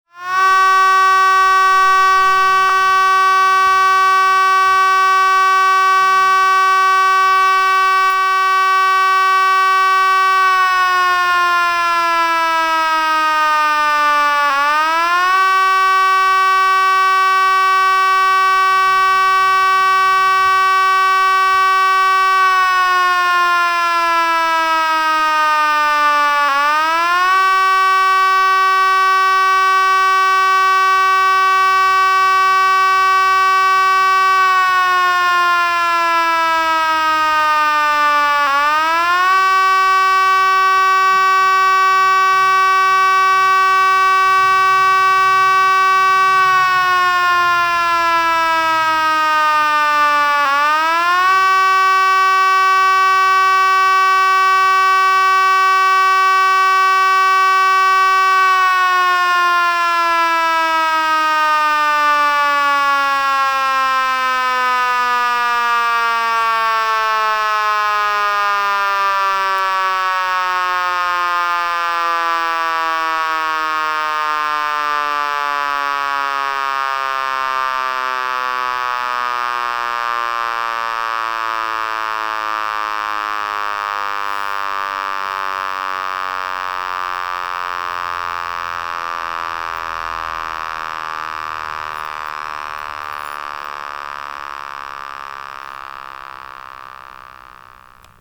SON SIRENE SAIP
Un essai est programmé tous les premiers mercredis de chaque mois, et le signal sonore lors d'un évènement est différent.
SON SIRENE SAIP.m4a